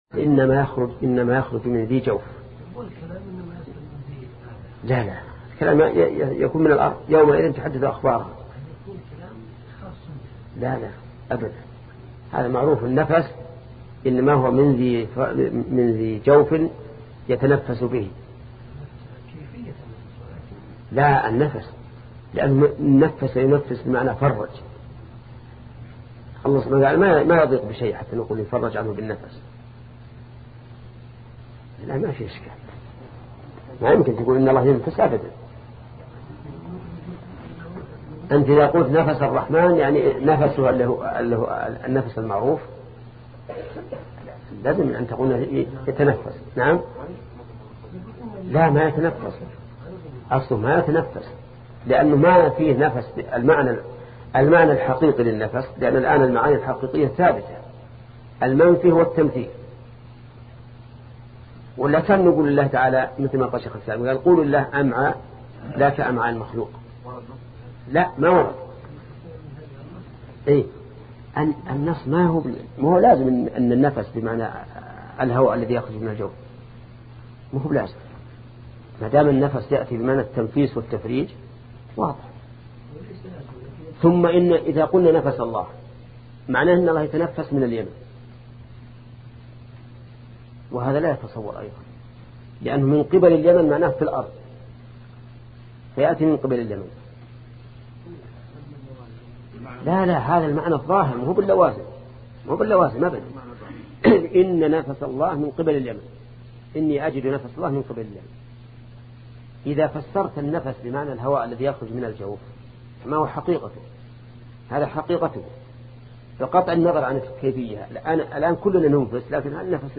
سلسلة مجموعة محاضرات شرح القواعد المثلى لشيخ محمد بن صالح العثيمين رحمة الله تعالى